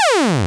pit_trap_fall.wav